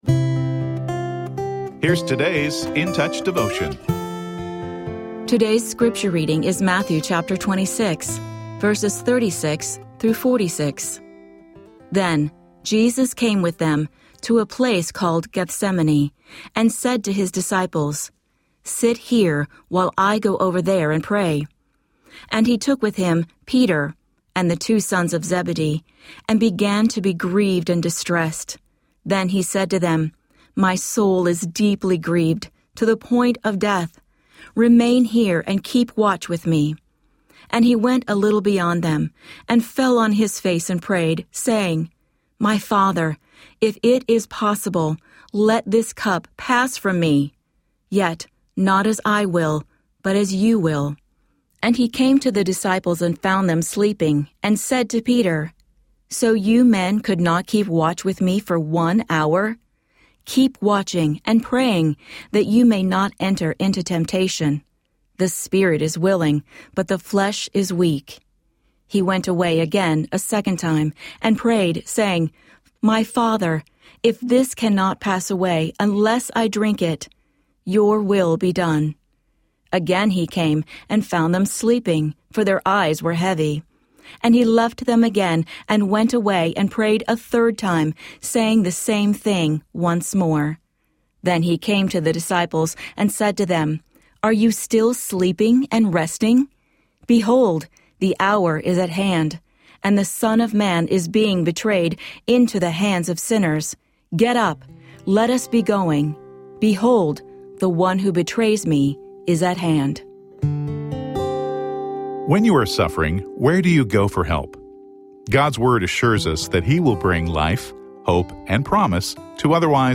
Daily audio devotional from Charles Stanley’s In Touch Ministries.